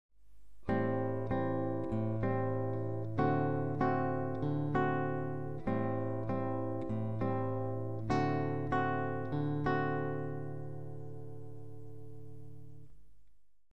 Rythmes Bossa 1
bossa2.mp3